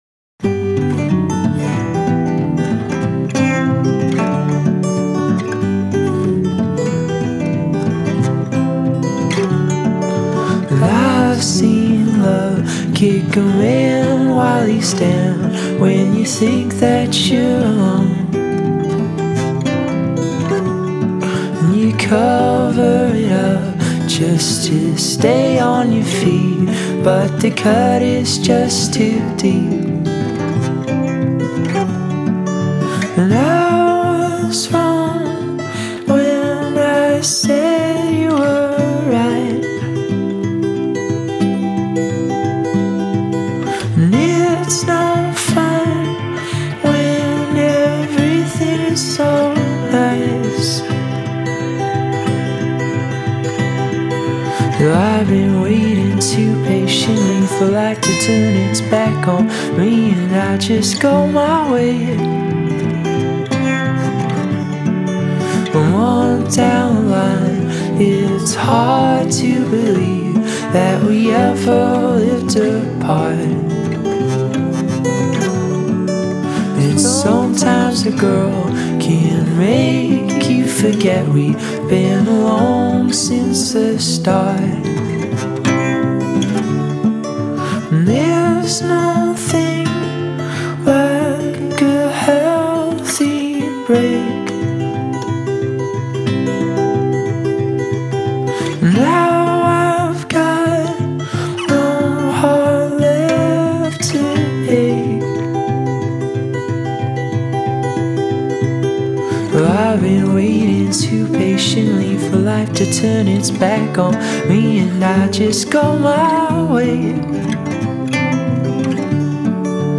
little acoustic number